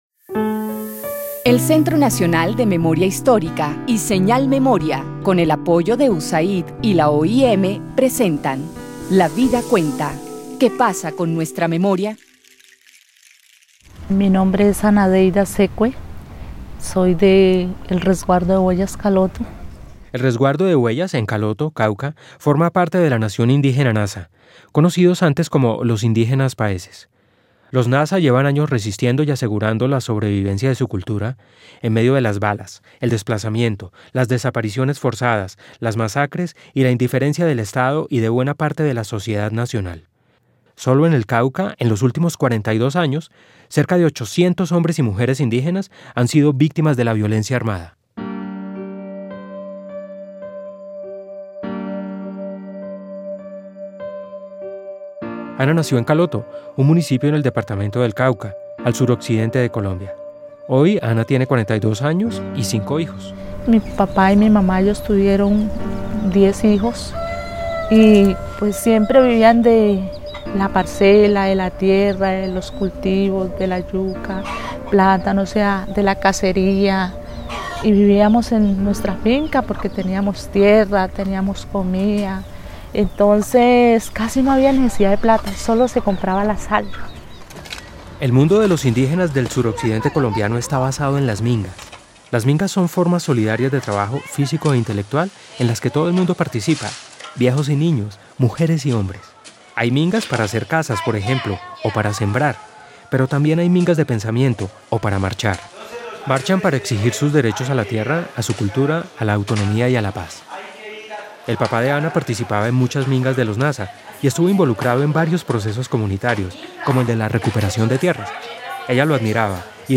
(tomado de la fuente) Audiencia (dcterms:audience) General Descripción (dcterms:description) Serie radial basada en el informe ¡Basta ya! Colombia: memorias de guerra y dignidad.